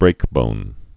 (brākbōn)